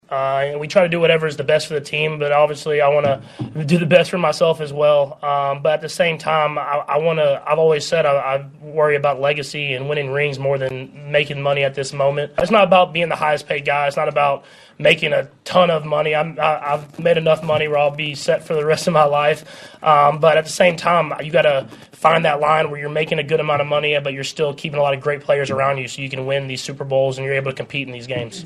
Mahomes was also asked about his contract.
5-25-patrick-mahomes-contract.mp3